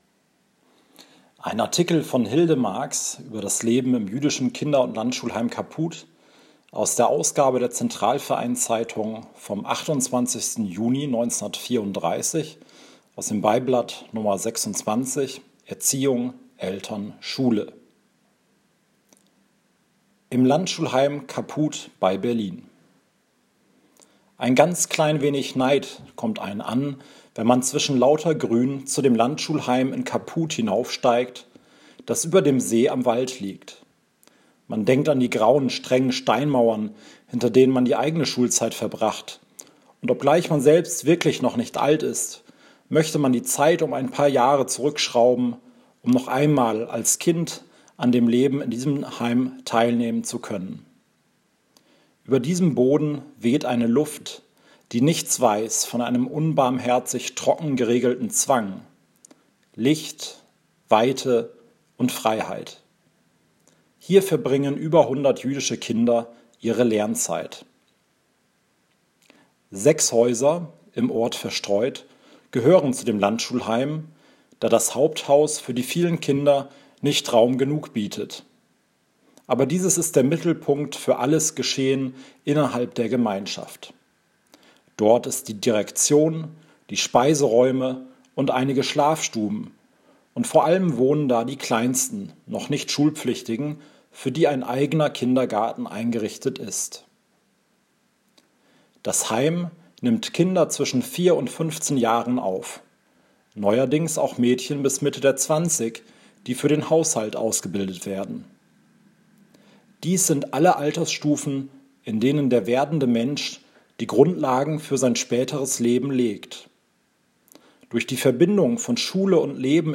Artikel (vorgelesen) aus der Central-Verein-Zeitung vom 28.6.1934 (m4a Audio Datei)